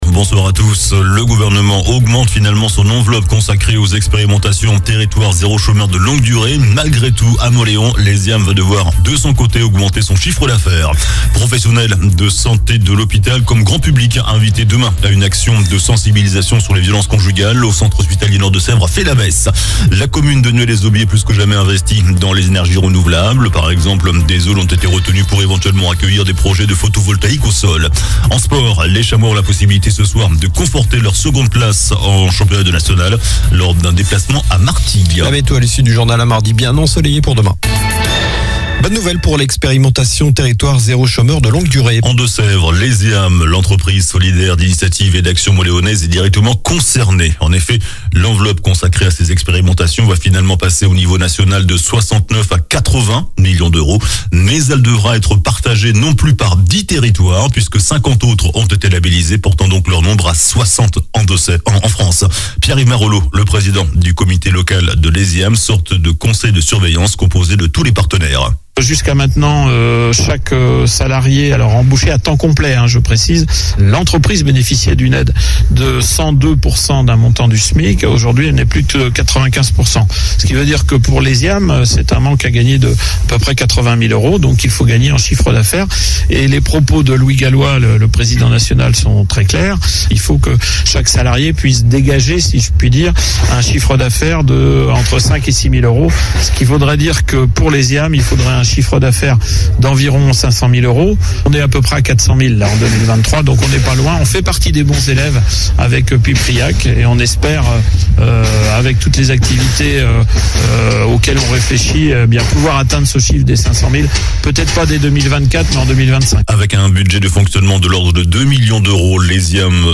JOURNAL DU LUNDI 04 DECEMBRE ( SOIR )